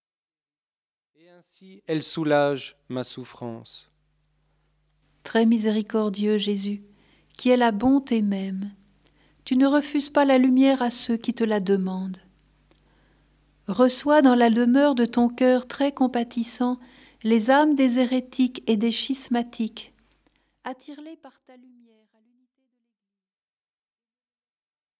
Prières, chants, et enseignements.